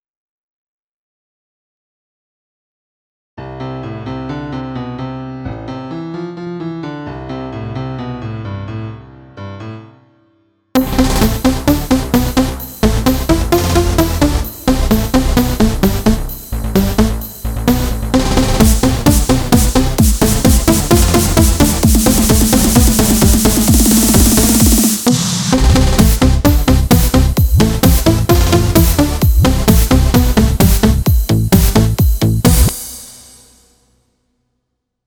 כמובן מיקס ויותר גיוון בסאונדים בפזמון לא יזיק - אבל עדיין טירוף - ונשמע ממש טוב…